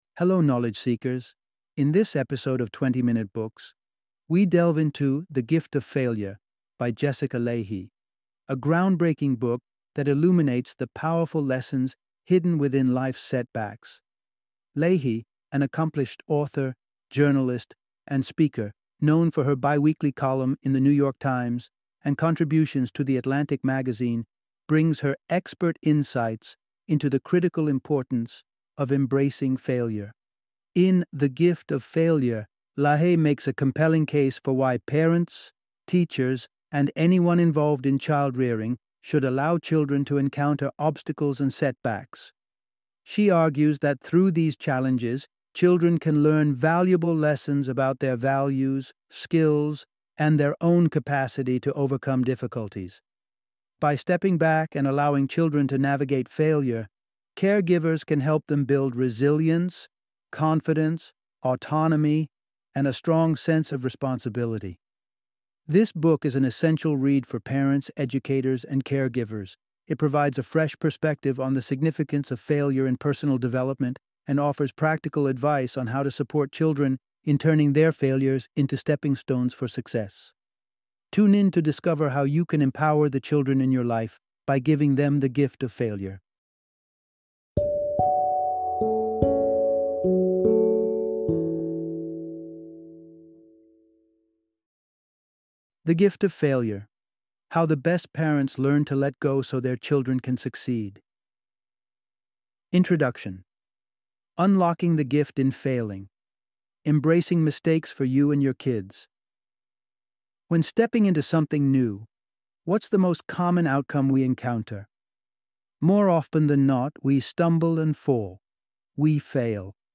The Gift of Failure - Audiobook Summary